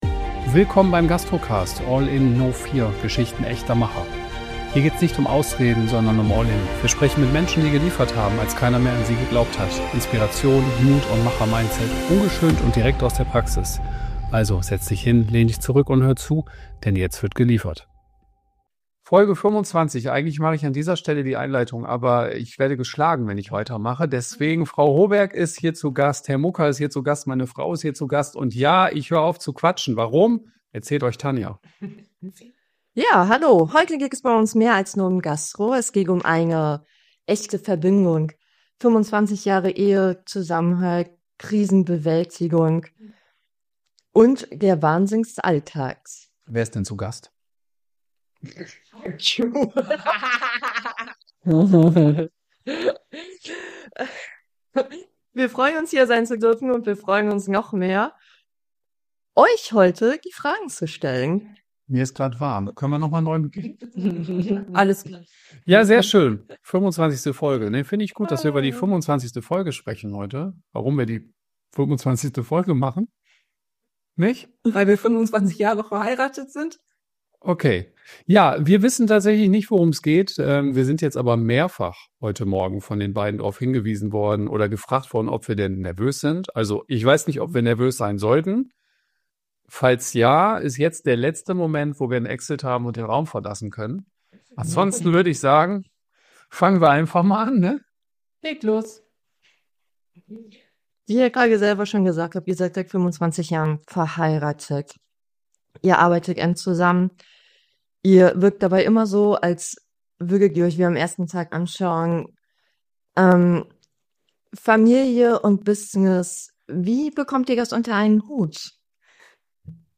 Was passiert, wenn dein eigenes Team dich interviewt – ohne Vorwarnung, ohne Skript?